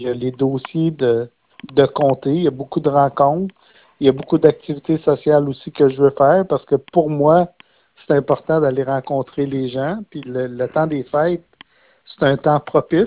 En entrevue, il a précisé qu’il va continuer de travailler sur les dossiers de la région.